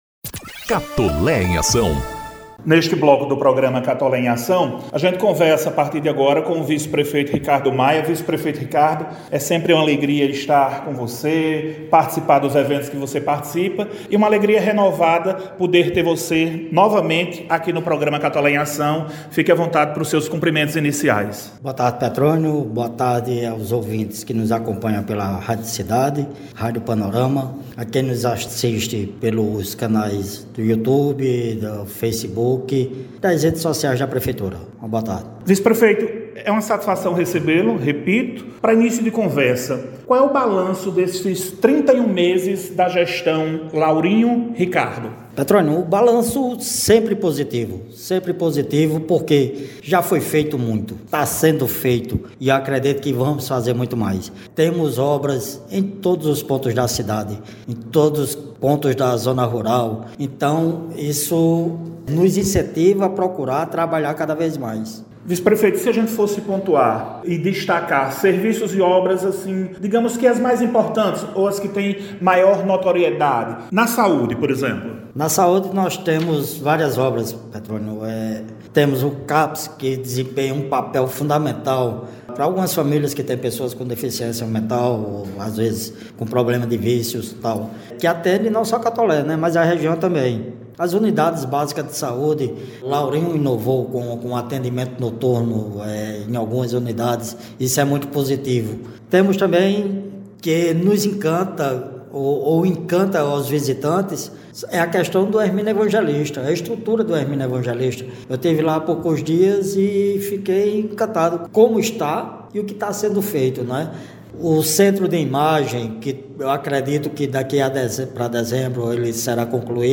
Vice-prefeito Ricardo Maia fez avaliação da gestão municipal. Ouça a entrevista! - Folha Paraibana
O Programa Institucional “Catolé em Ação” – edição n° 101 – exibiu entrevista com o vice-prefeito Ricardo Maia, sexta-feira (11/08).